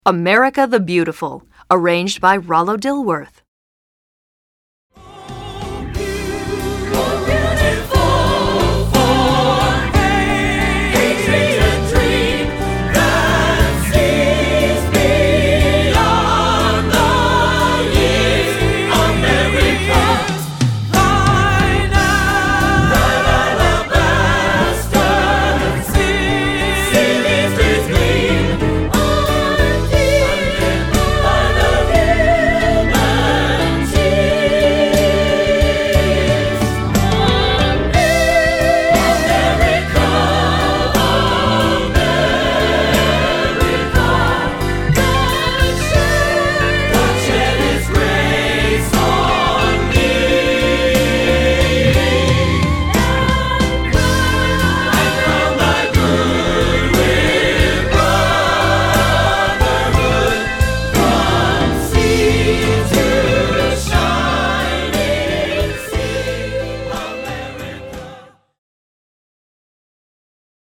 Choral Patriotic
SATB